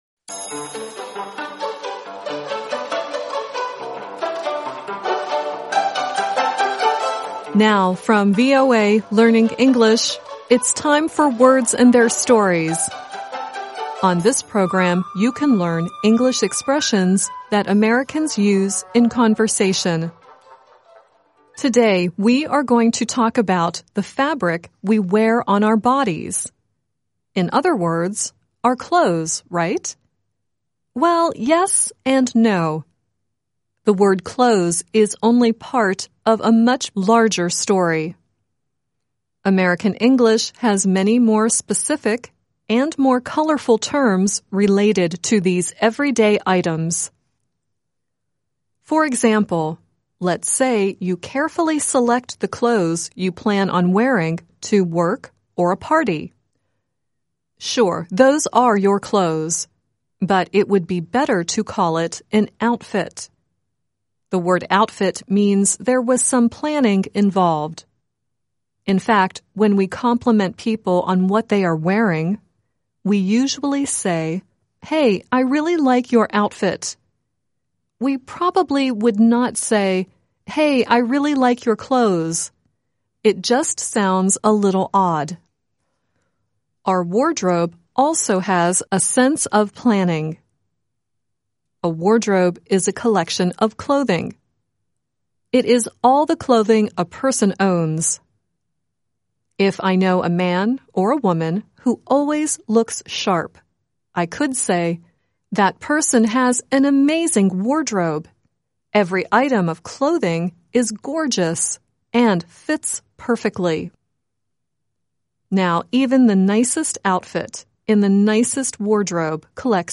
The song at the end is Don Henley singing "Dirty Laundry."